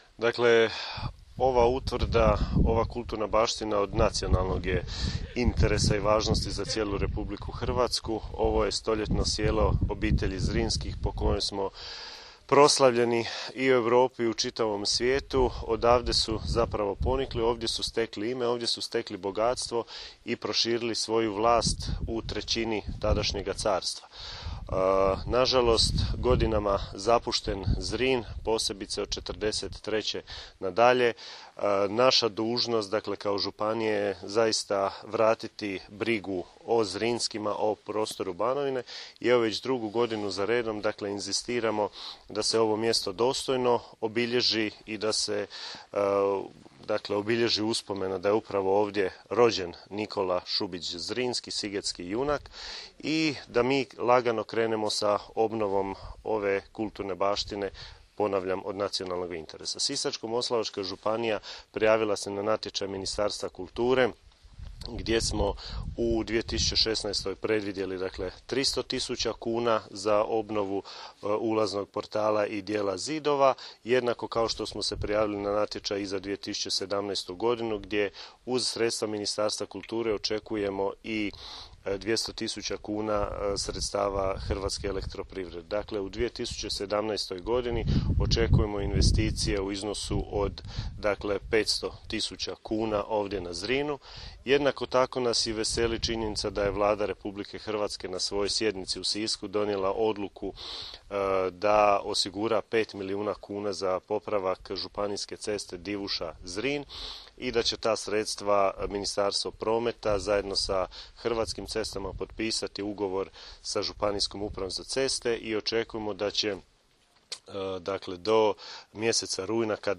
Izjavu, koju je nakon upoznavanja s tijekom radova dao zamjenik župana Piletić, možete poslušati ovdje: